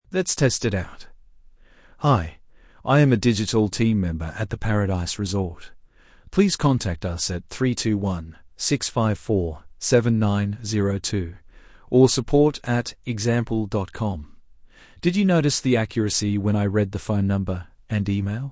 Premium Voices suitable for real-time streaming.
British
masculine, warm, approachable, trustworthy, baritone